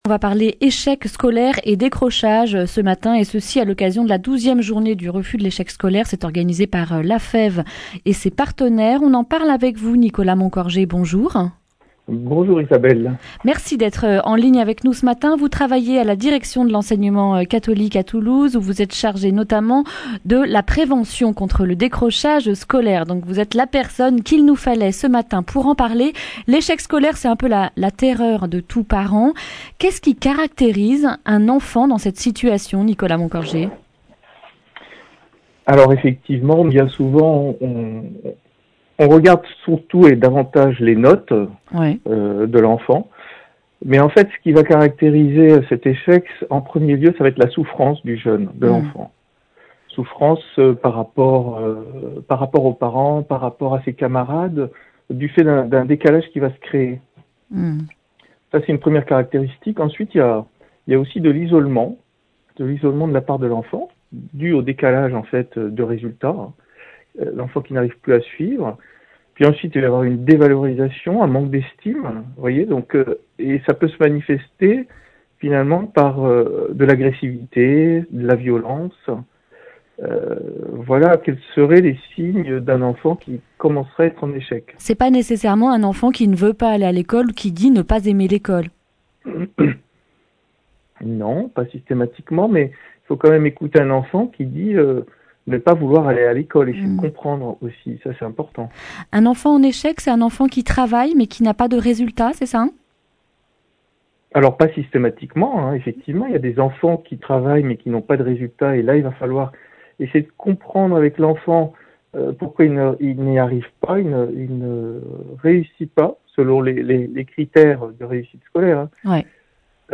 mercredi 25 septembre 2019 Le grand entretien Durée 11 min